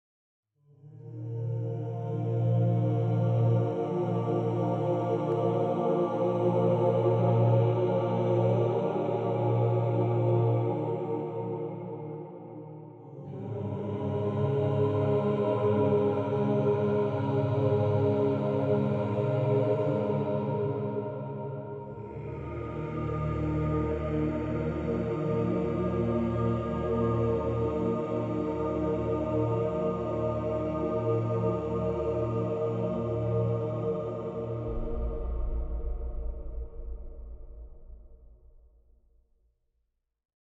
chorus-chant.ogg